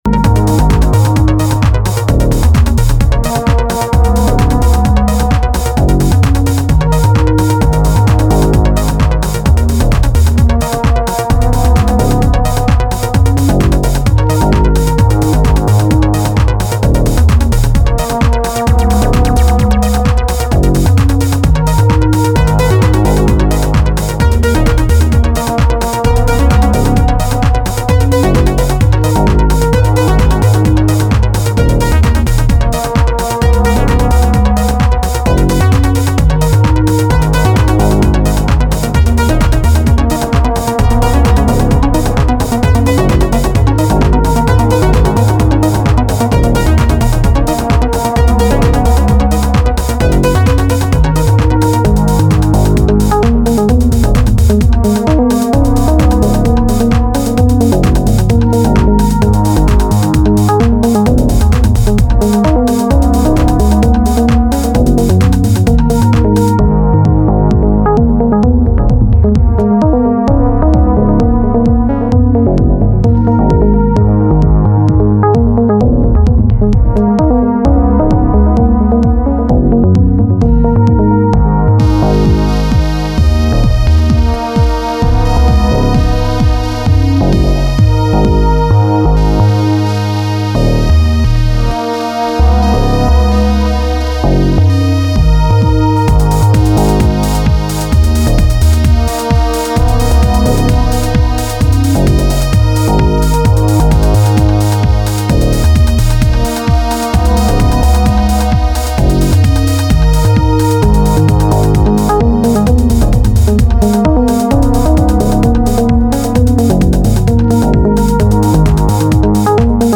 evocative and playful melodies